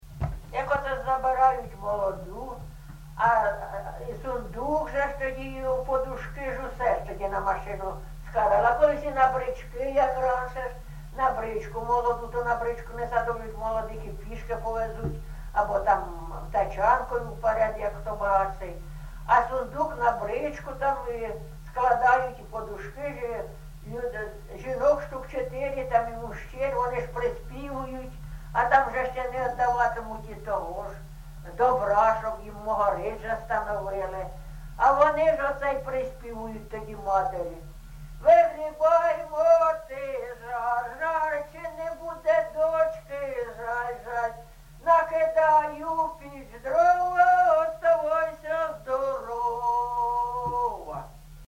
ЖанрВесільні
Місце записус. Софіївка, Краматорський район, Донецька обл., Україна, Слобожанщина